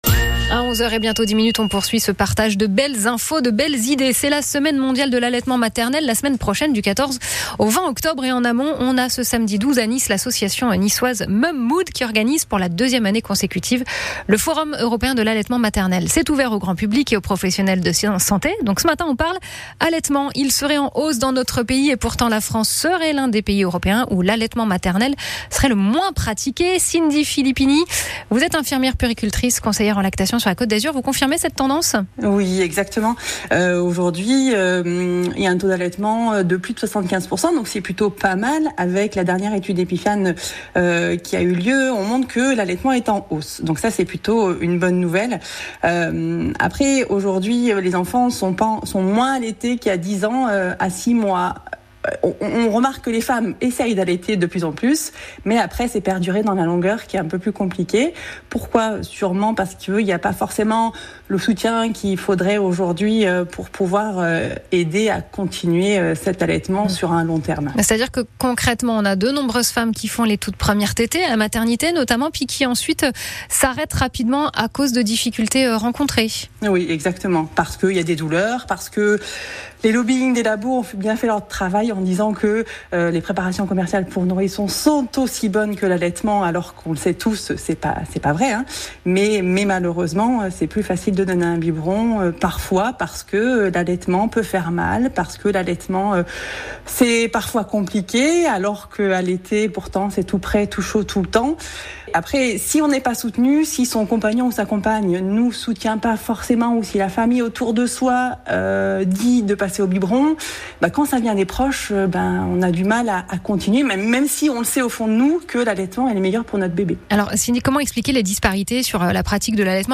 👉 Écouter l’interview complète ici de Radio France Bienvenue chez vous